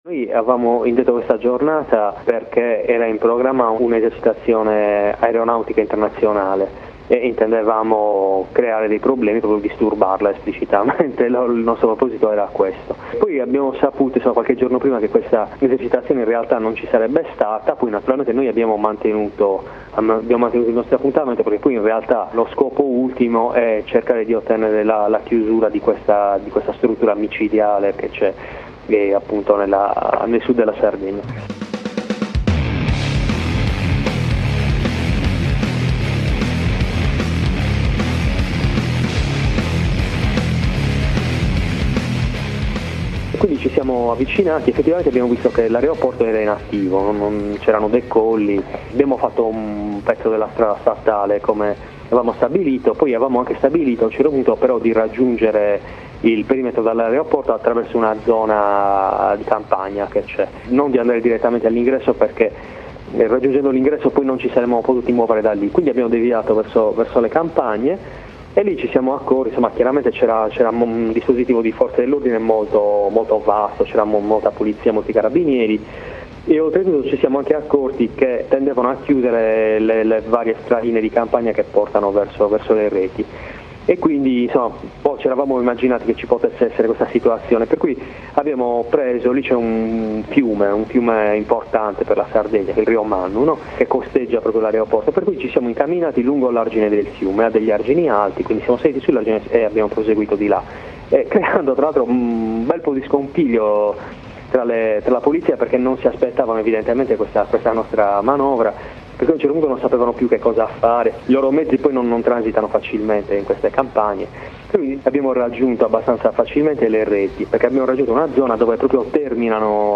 Dopo la manifestazione dell’11 giugno 2015 contro l’aeroporto militare di Decimomannu, abbiamo sentito alcuni compagni sardi per farci raccontare quella giornata e altre mobilitazioni contro le basi militari dell’ultimo periodo in Sardegna.